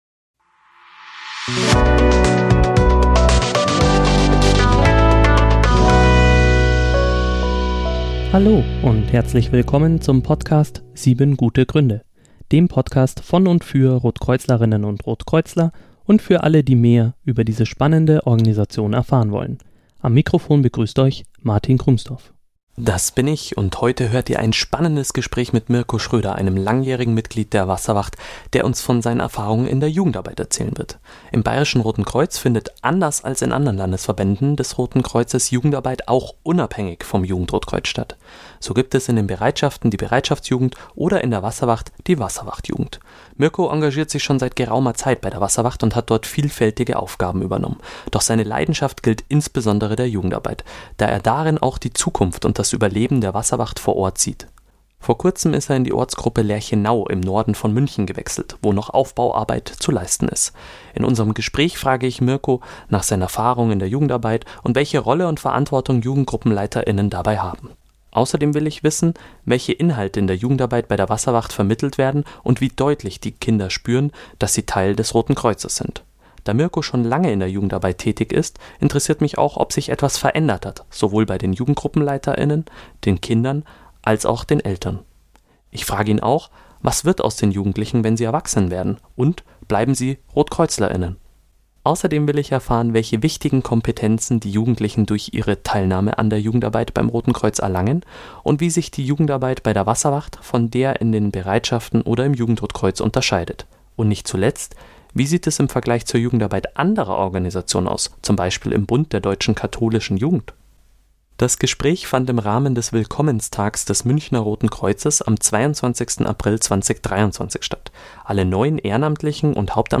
Das Gespräch fand im Rahmen des Willkommenstags des Münchner Roten Kreuzes am 22. April 2023 statt.